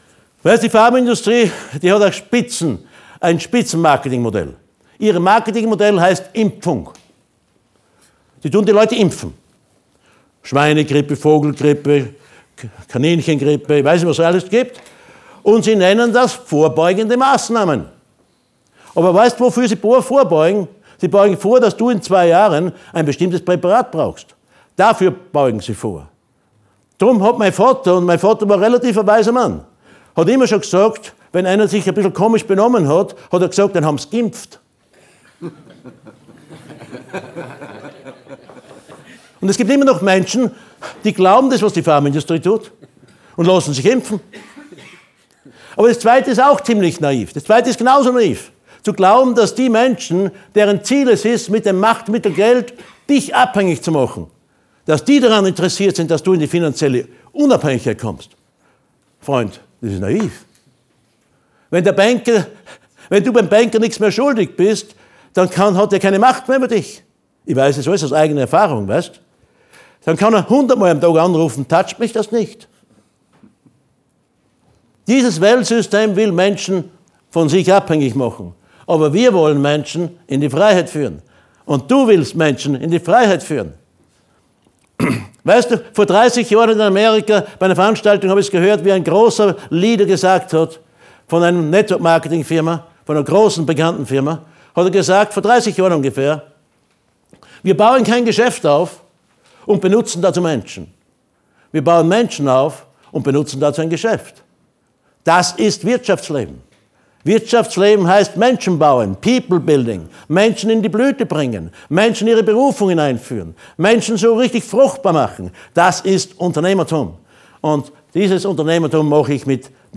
LIVE-Mitschnitt Teil 02